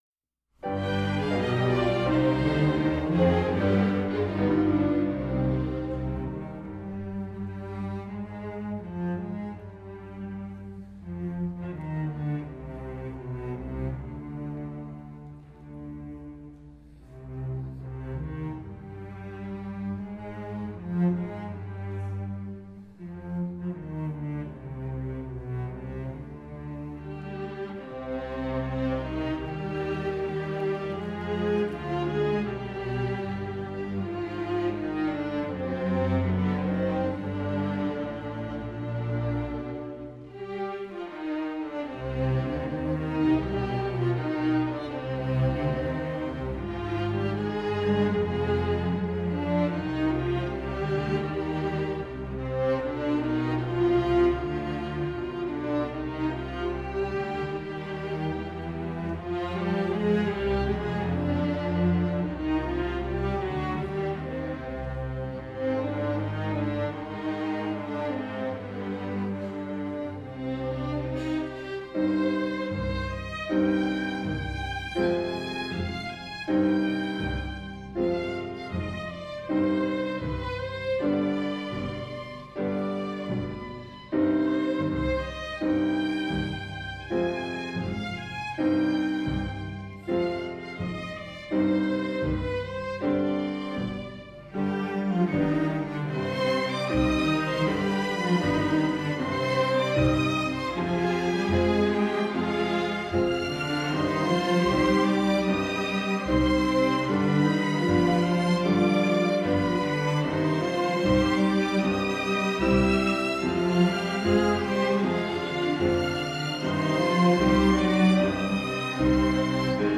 Instrumentation: Piano and string orchestra
Ensemble: String Orchestra